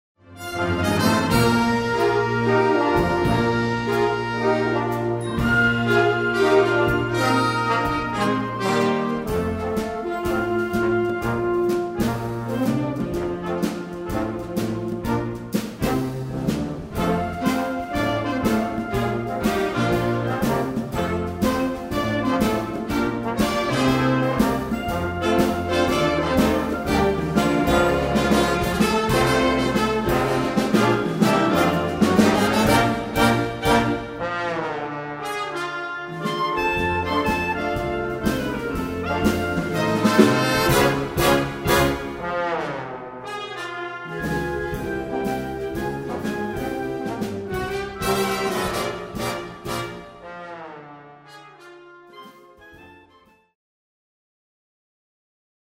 Puhallinorkesteri / Wind Orchestra Grade 3-5